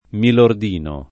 milord [ingl. mil0od] s. m. — propr., adattam. fr. dell’ingl. my lord «mio signore» — spesso pronunziato, in It., mil0rd; antiq. l’adattam. pop. milorde [mil0rde] o milordo [mil0rdo] — dim. milordino [